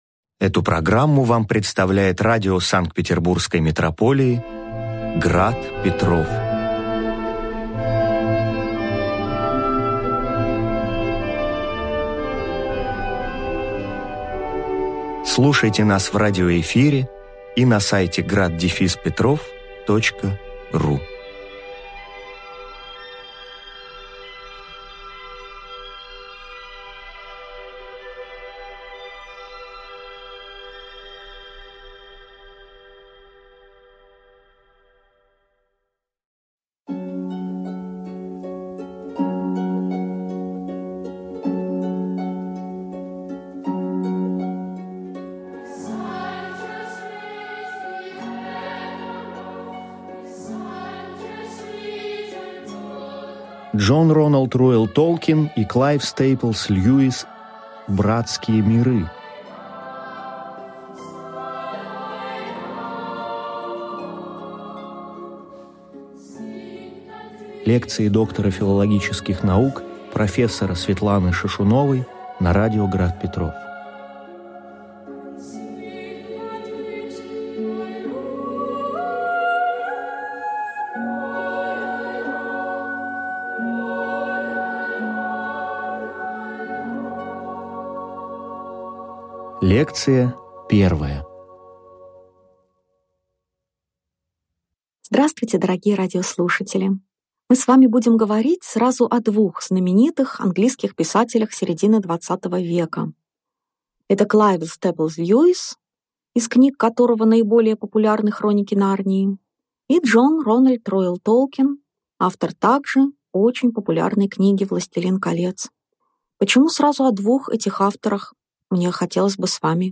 Аудиокнига Лекция 1. Зачем говорить о Дж.Р.Р.Толкине и К.С.Льюисе на православном радио?